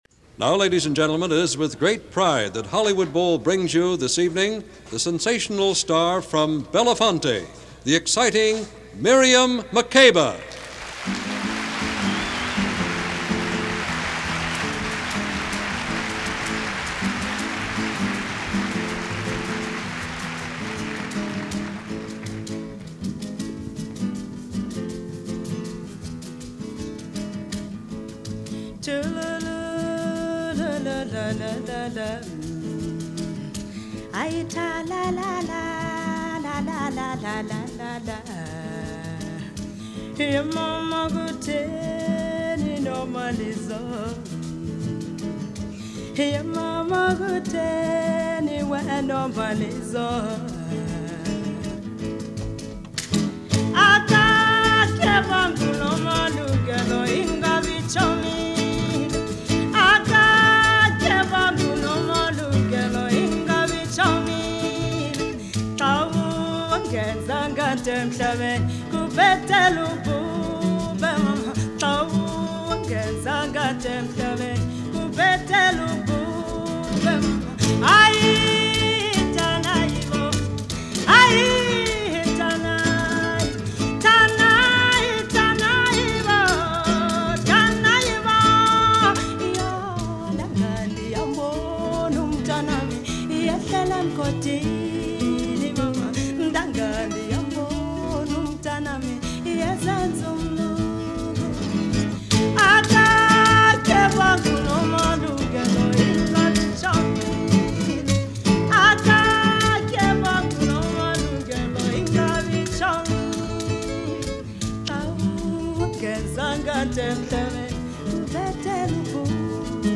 In Concert At The Hollywood Bowl – September 1962